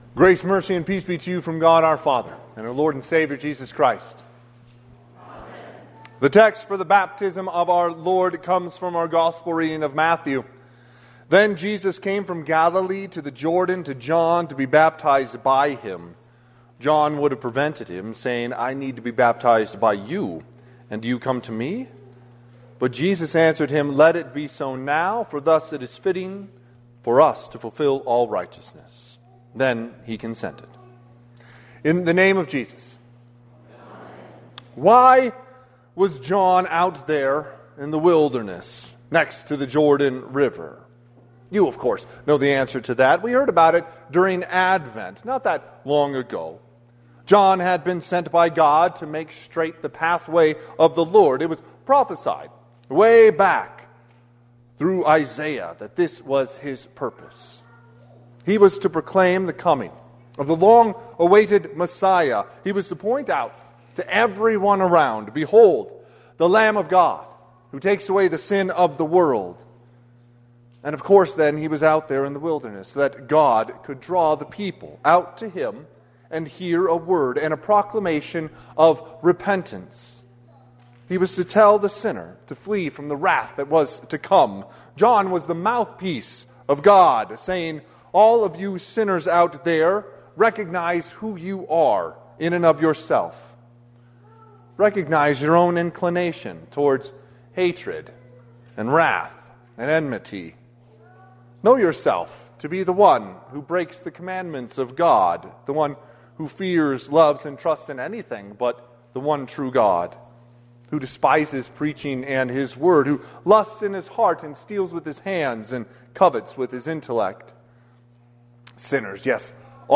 Sermon - 1/11/2026 - Wheat Ridge Evangelical Lutheran Church, Wheat Ridge, Colorado